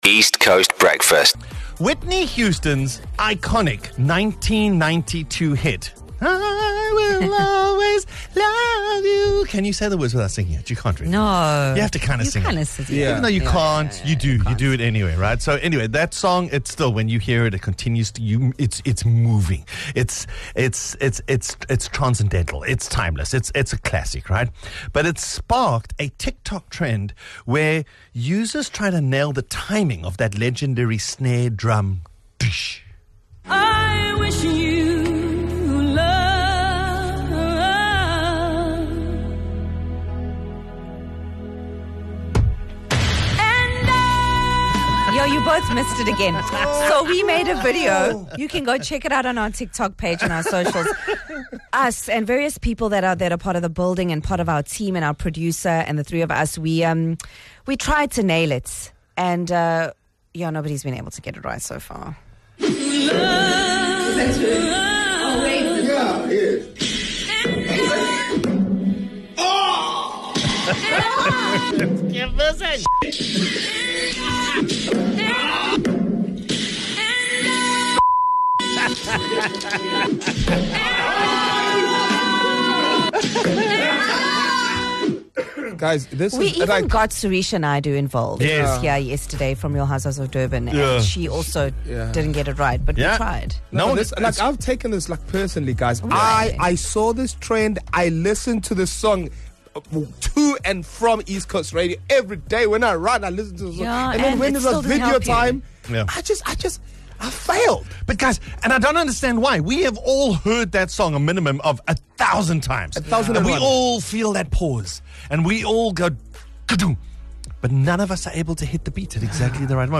Drivers across the province gave it their best shot, but…